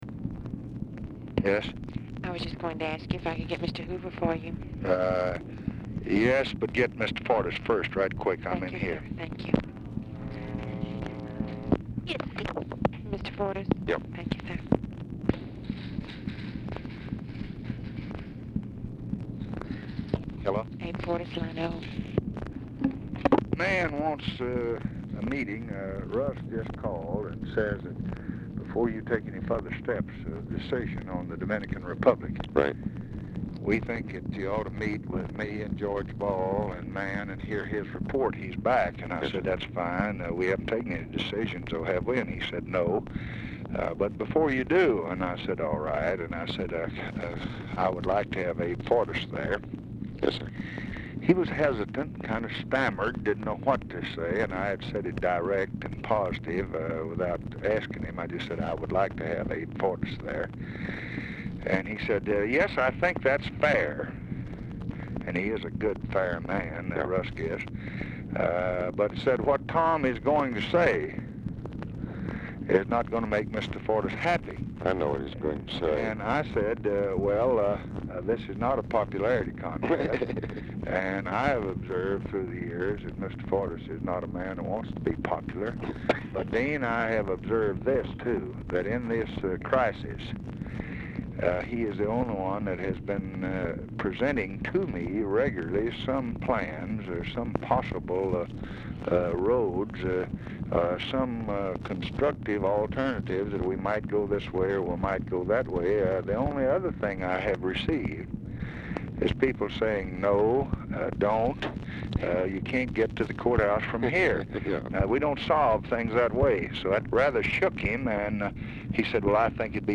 Telephone conversation # 7778, sound recording, LBJ and ABE FORTAS, 5/19/1965, 11:40AM | Discover LBJ
Format Dictation belt
Location Of Speaker 1 Oval Office or unknown location
Specific Item Type Telephone conversation